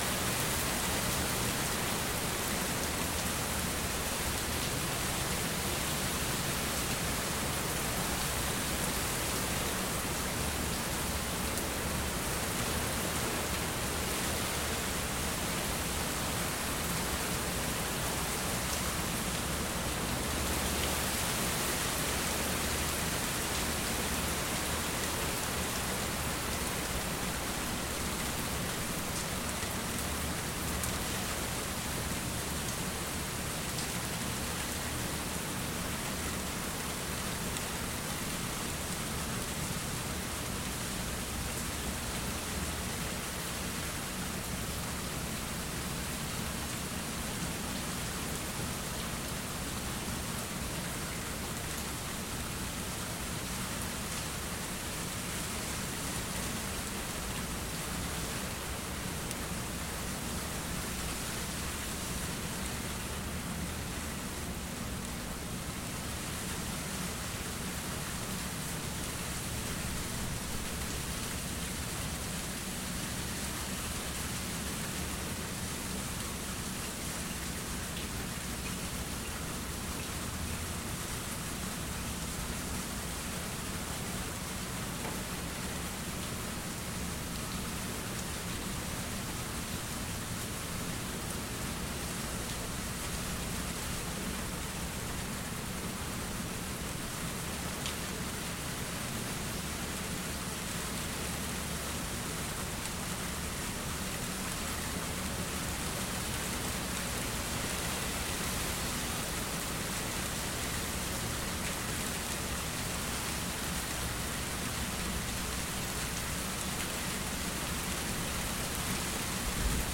内布拉斯加州林肯市龙卷风5 9 2016年
描述：龙卷风的警报声响起了一股强大的雷雨，包裹着龙卷风，大雨和冰雹滚过内布拉斯加州的林肯市。
标签： 暴风 天气 严重 雷电 冰雹 雷暴 龙卷风 NATU重 现场记录 警报器
声道立体声